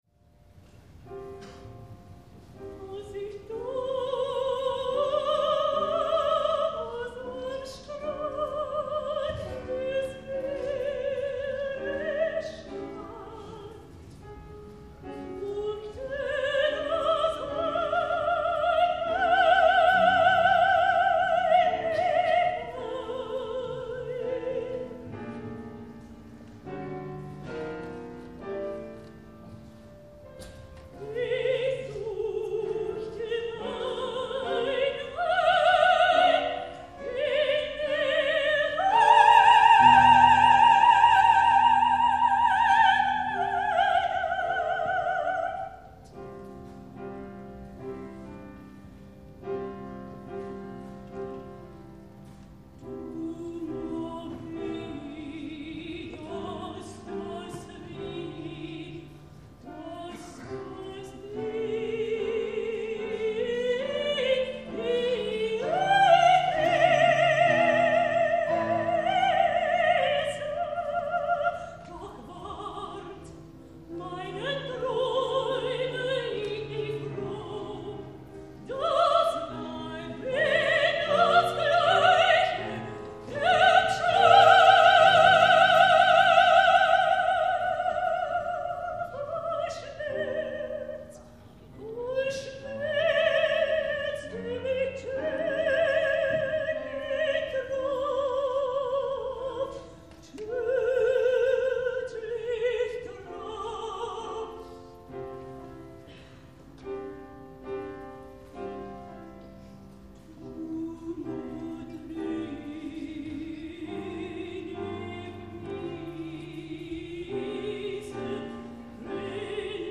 Jonny spielt auf (MP-3) Fragment 1 Een opera van Krenek uit 1927 (Uitvoering Operhaus Wuppertal 2002 )